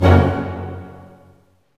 Index of /90_sSampleCDs/Roland L-CD702/VOL-1/HIT_Dynamic Orch/HIT_Orch Hit Maj
HIT ORCHM0BR.wav